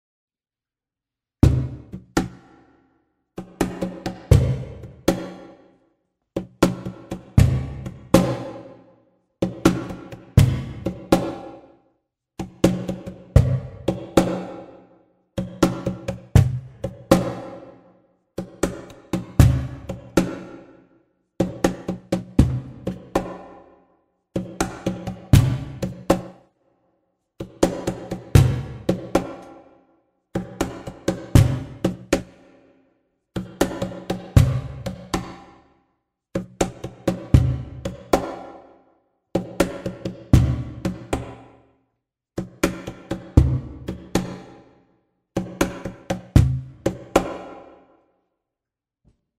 Per provare il nostro preamp abbiamo registrato una song con cajon, chitarra acustica e voce (tutti ripresi con il Tube Box ed un microfono Microtech Gefell M940).
Cajon
Cajon.mp3